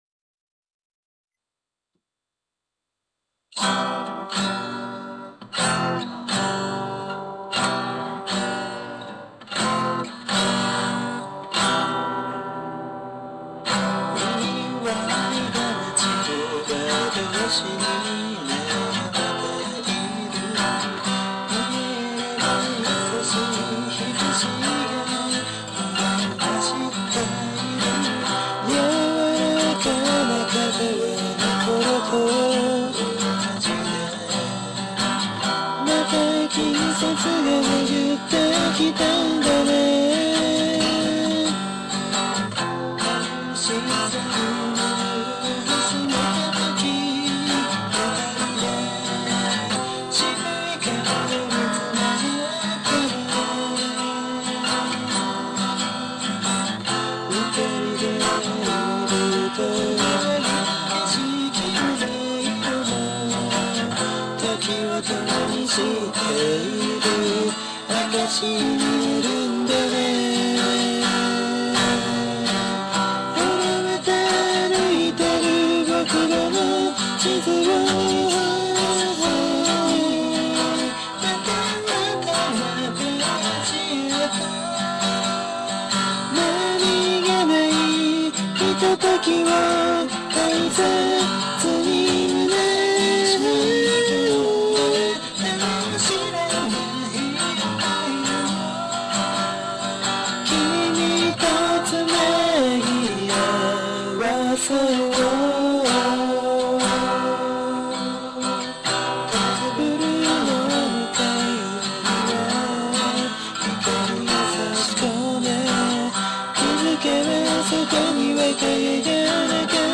覚悟を決めて次のステージへいくぞという気持ちを表現したかったため、はじめて転調を入れた曲です。
デモテープ音源